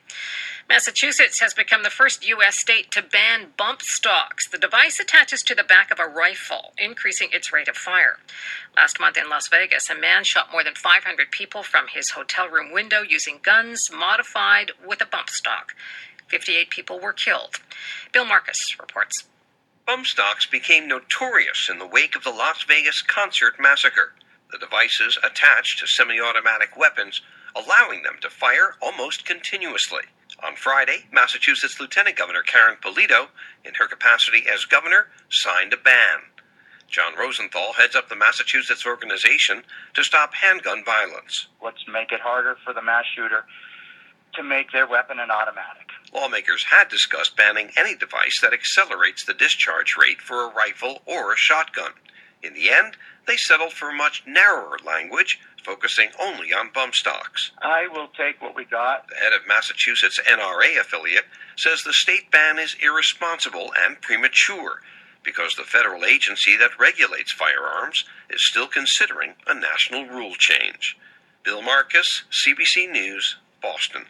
CBC-WORLD-REPORT-7AM-4-NOV.mp3